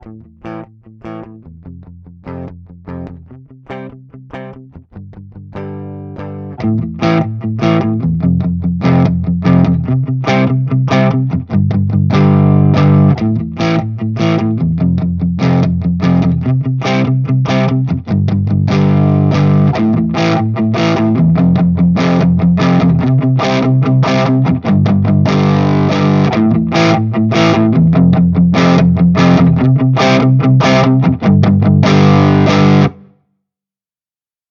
anstelle einer ampsim...
1 pur
2 crtiv und ignite
3 crtiv, black box und ignite
4 tse 808, critv, blackbox und ignite
5 tse 808, blackbox, critv und ignite
ampsimplug.mp3